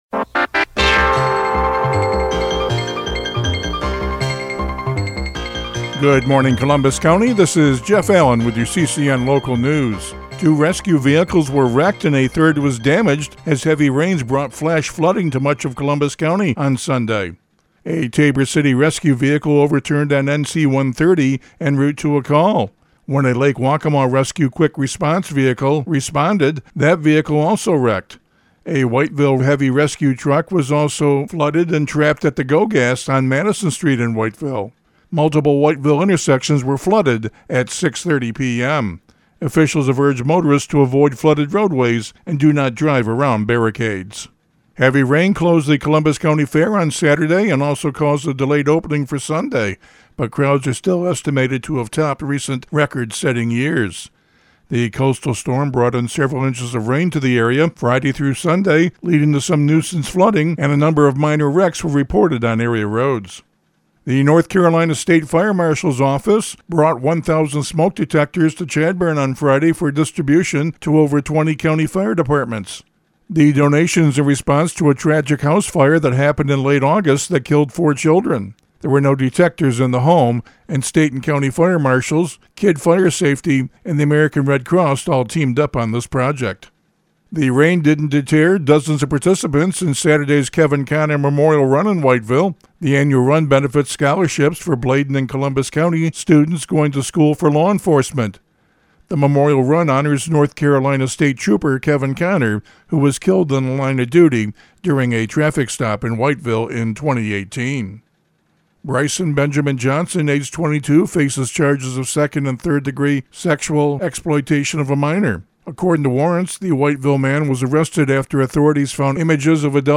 CCN Radio News — Morning Report for October 13, 2025